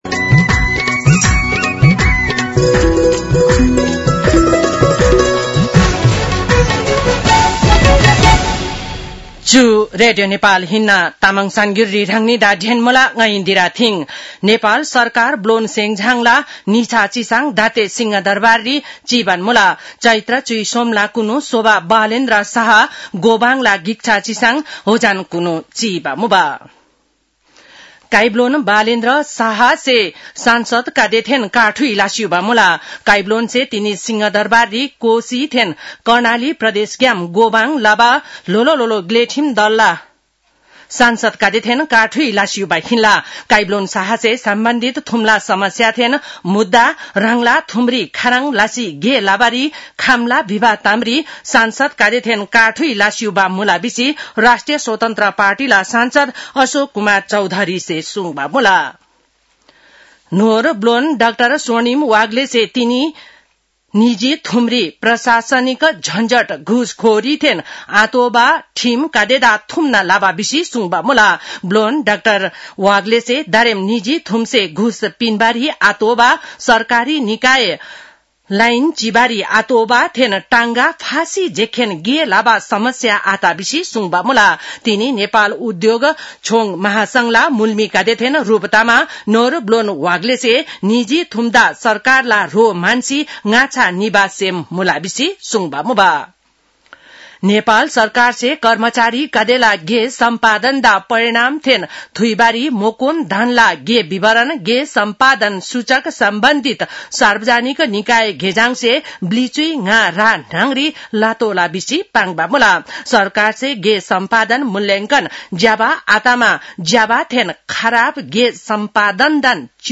तामाङ भाषाको समाचार : १६ चैत , २०८२